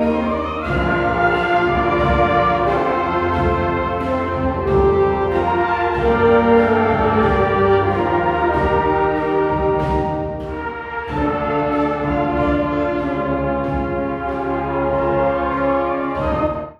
Kostproben vom Weihnachtskonzert 2024: